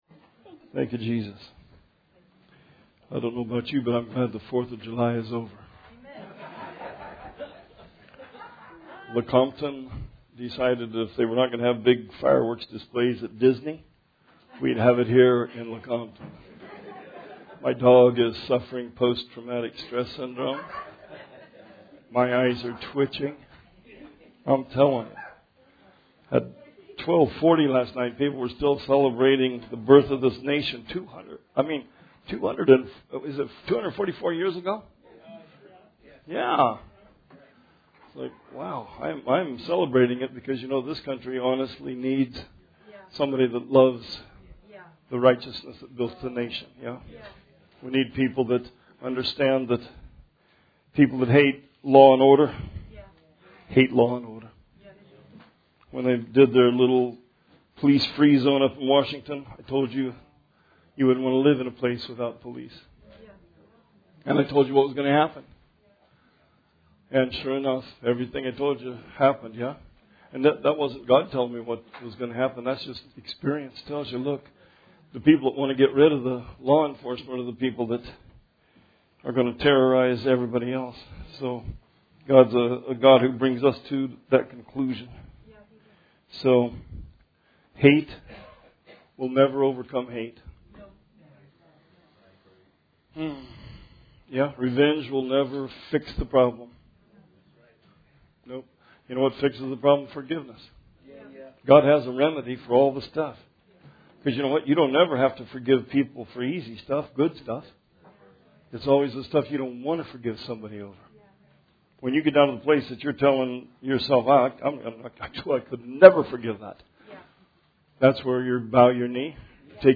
Sermon 7/5/20